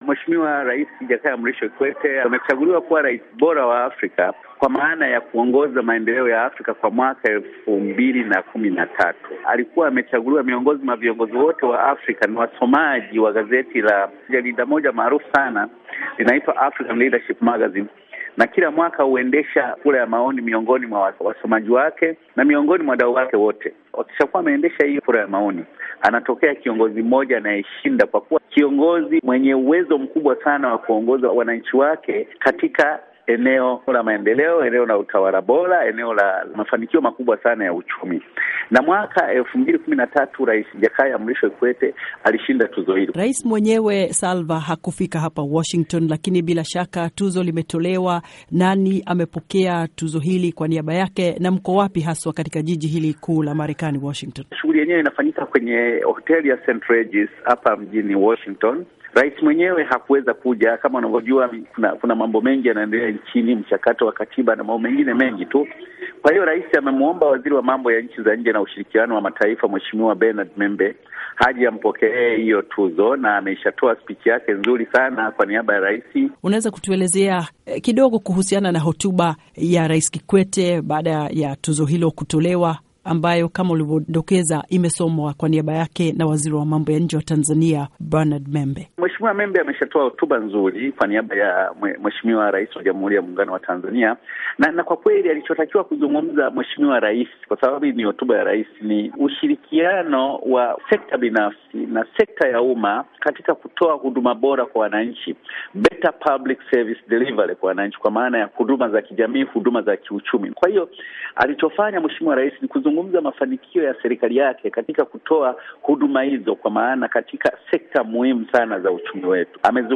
Mahojiano na Salva Rweyemamu